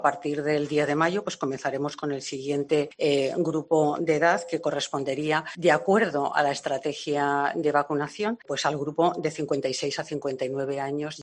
Directora General de Salud Pública de Madrid, Elena Andradas